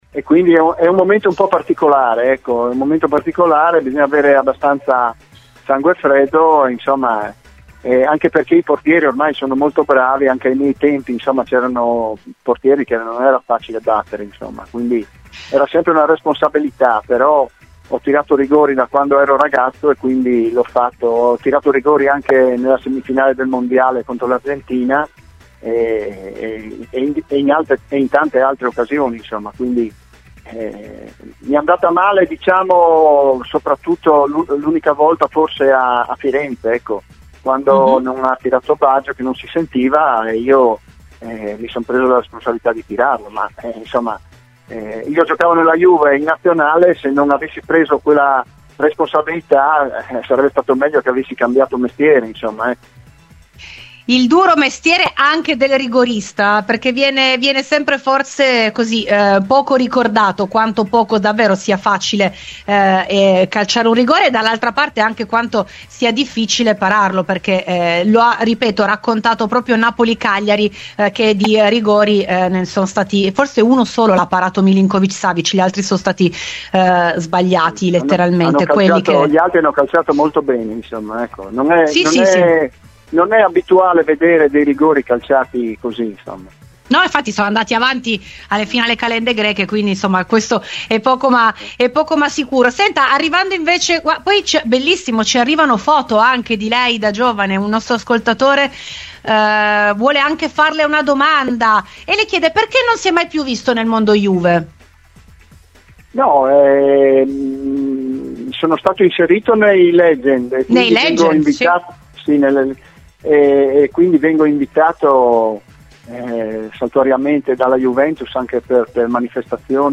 Gigi De Agostini ai microfoni di Rbn: l'ex giocatore della Juve analizza l'impegno di Napoli, suo l'ultimo rigore segnato in casa partenopea